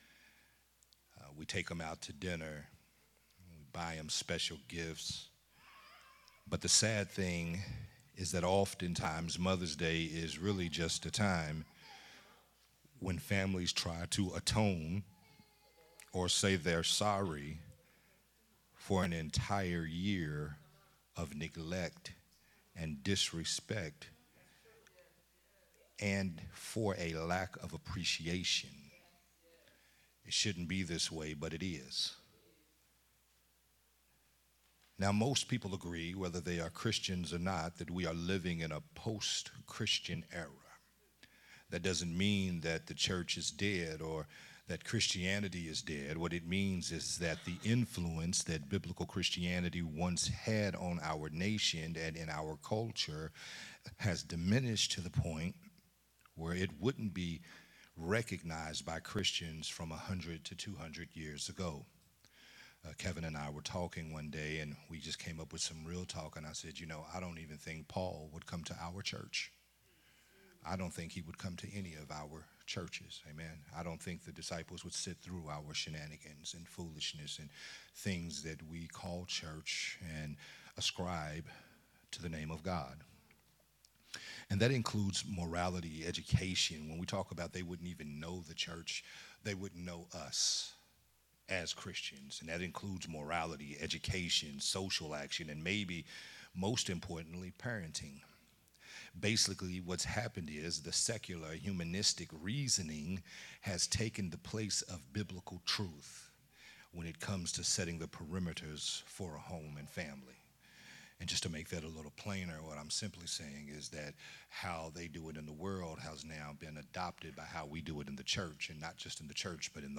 Sunday Morning Worship Service